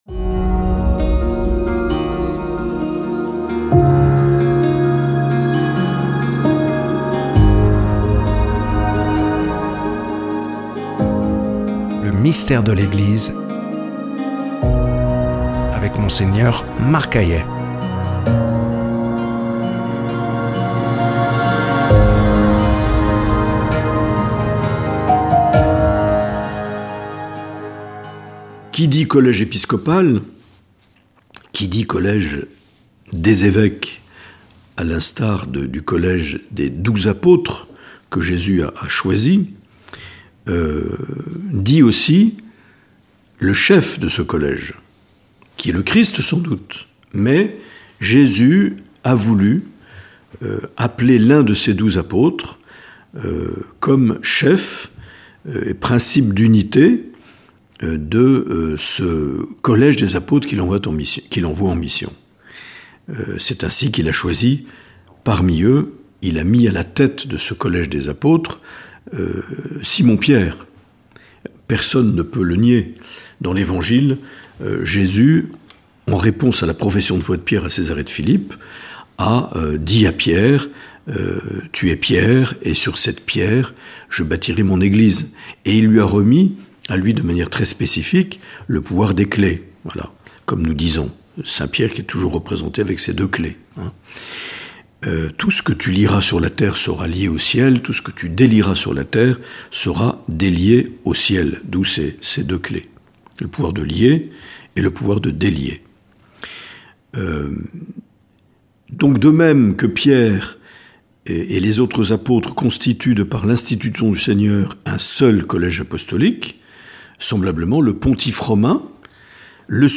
Mgr Marc Aillet nous propose une série de catéchèses intitulée "Le Mystère de l’Eglise" notamment à la lumière de la constitution dogmatique "Lumen Gentium" du concile Vatican II.